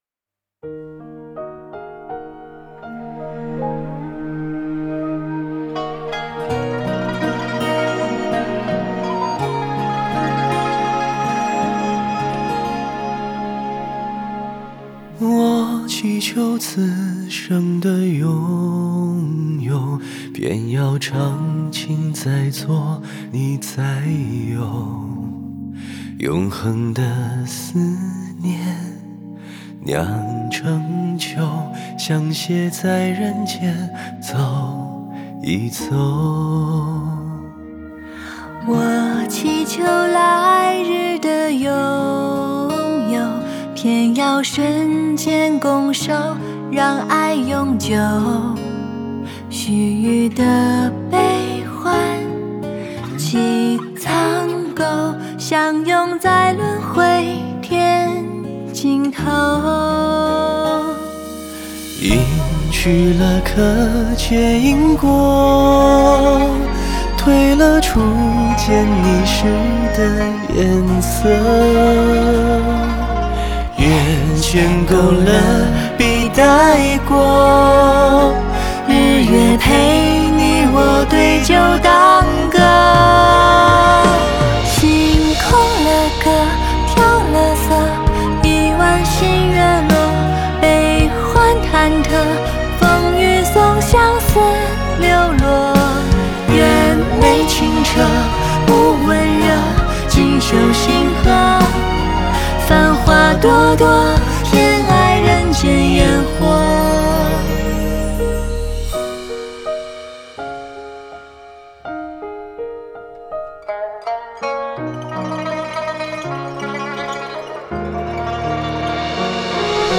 Ps：在线试听为压缩音质节选，体验无损音质请下载完整版
弦乐
笛子
琵琶